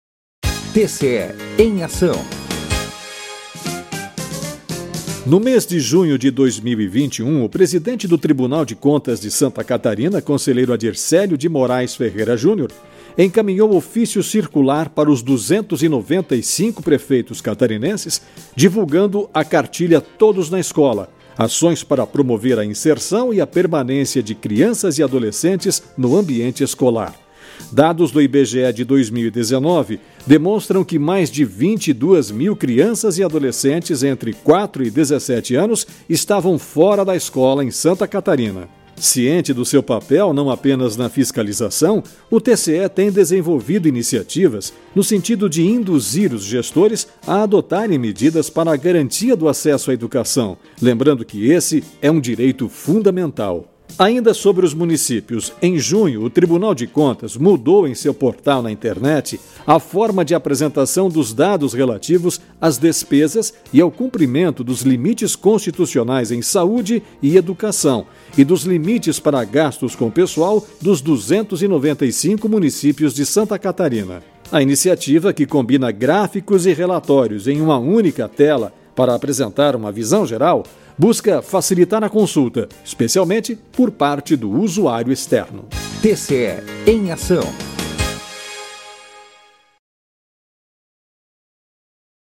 VINHETA – TCE EM AÇÃO